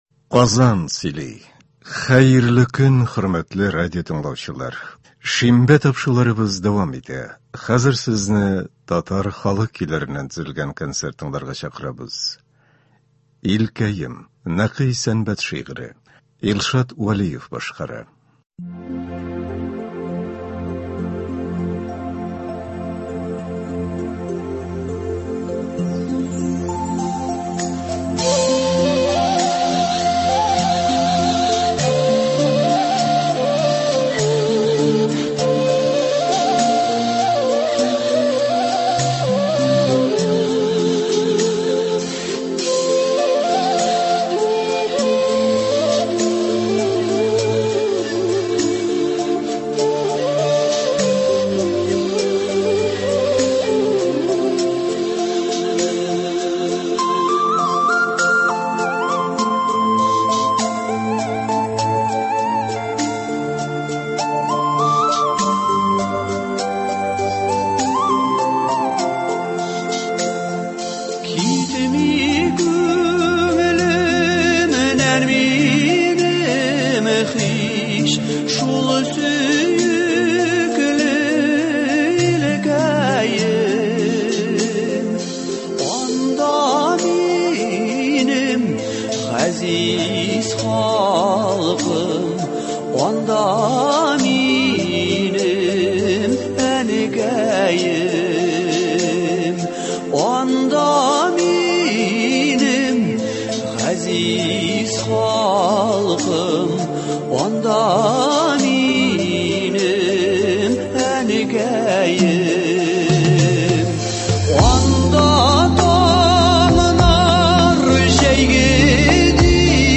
Татар халык көйләре (03.09.22)